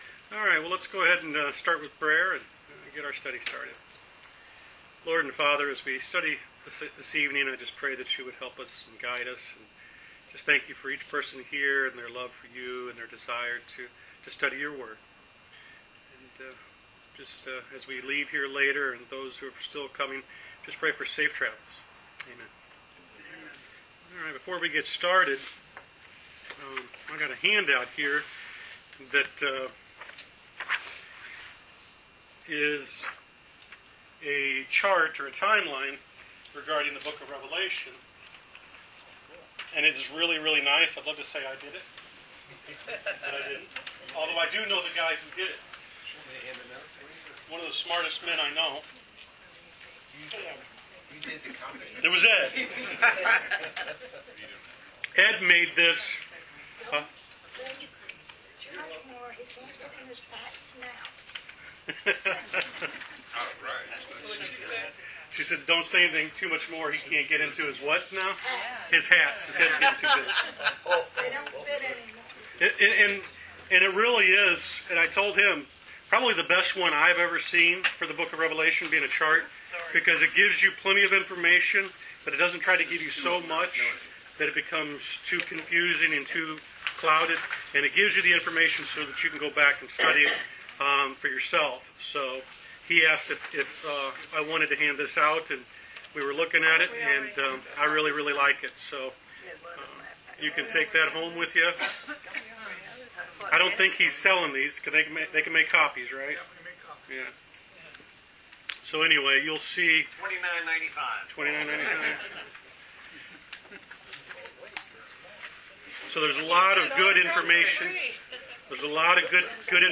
Wednesday Bible Study: Rev Ch 6 Pt 2